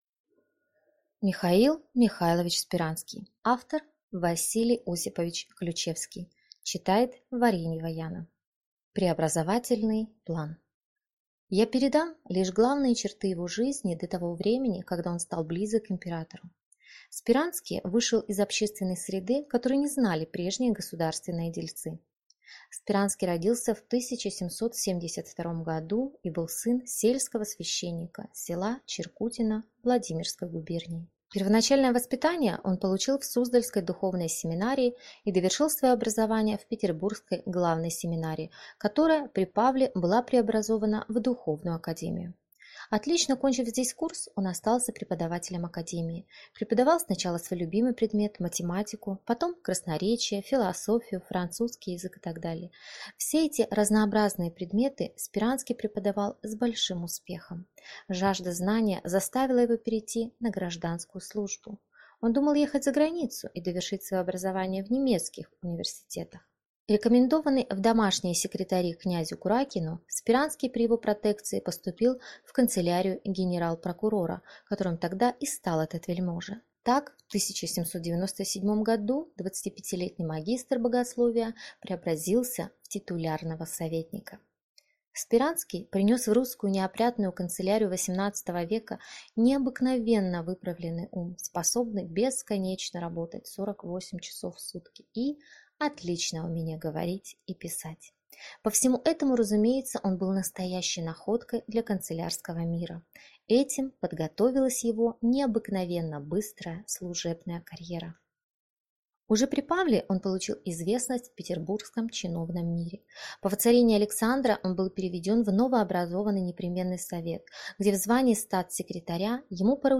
Аудиокнига М.М. Сперанский | Библиотека аудиокниг